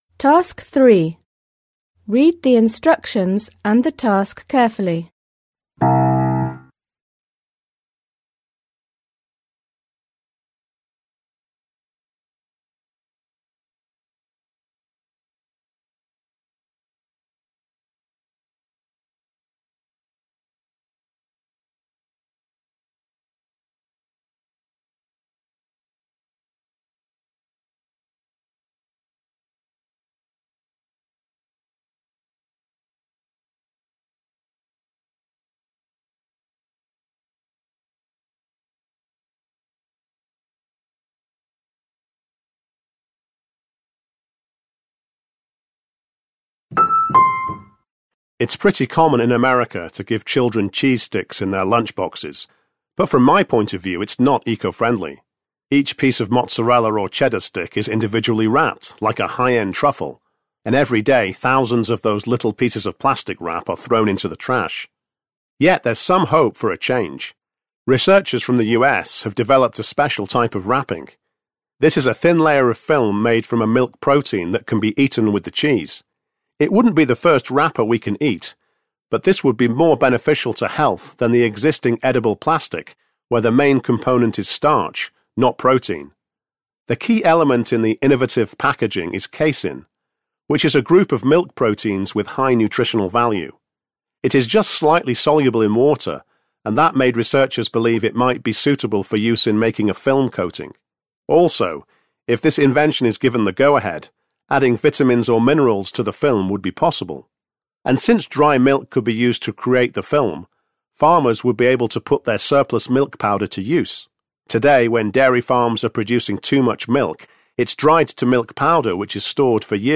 You are going to hear someone giving a speech on innovative food packaging.